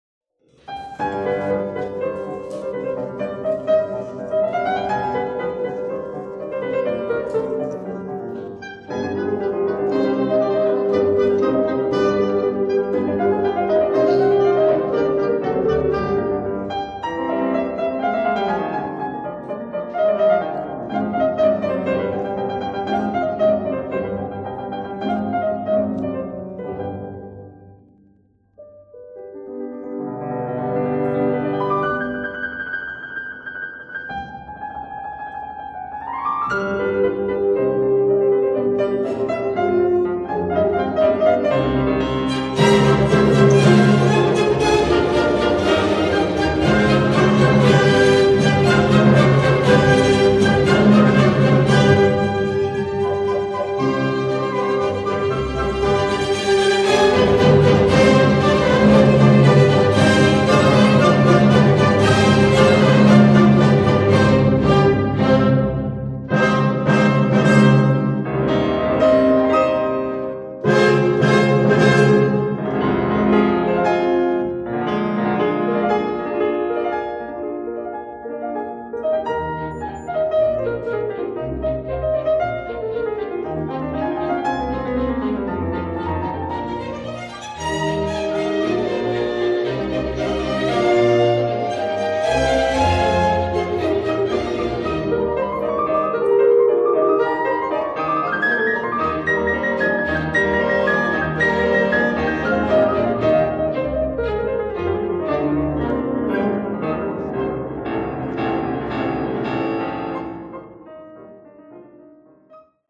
Klavier
08 Klavierkonzert.mp3